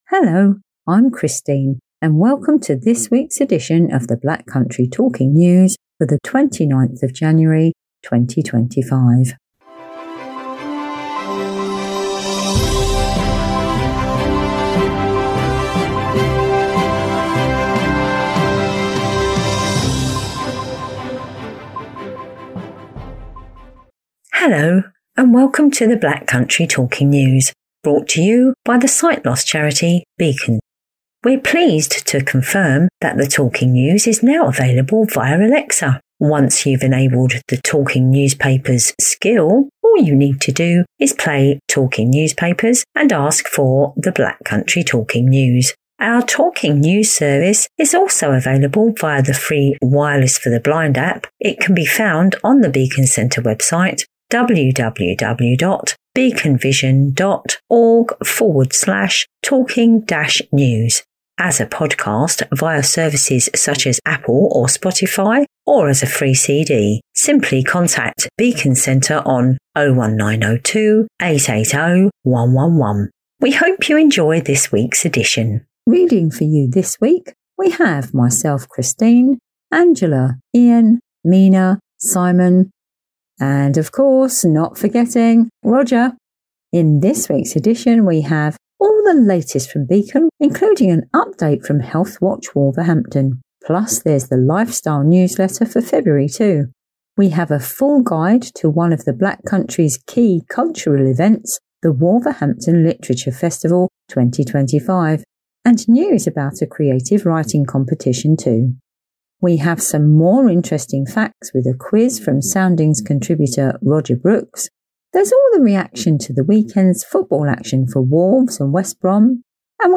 Also, keep your ears open for some pitch perfect piano playing too!